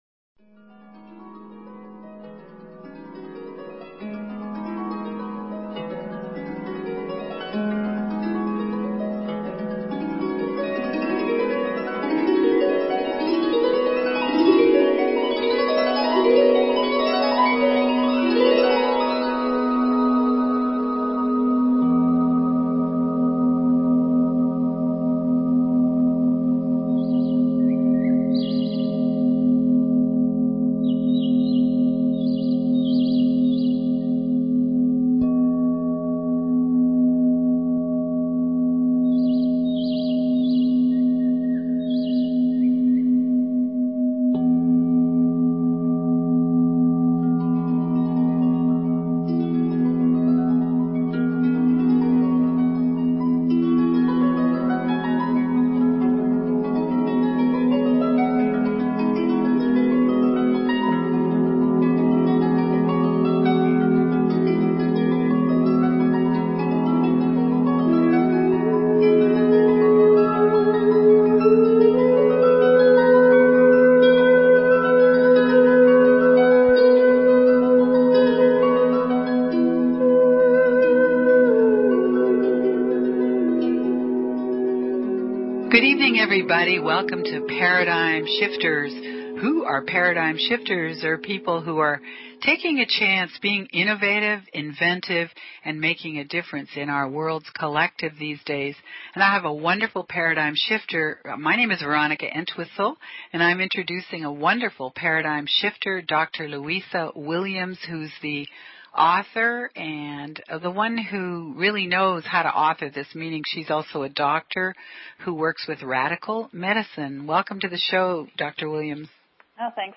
Talk Show Episode, Audio Podcast, Paradigm_Shifters and Courtesy of BBS Radio on , show guests , about , categorized as
Paradigm Shifters Talk Show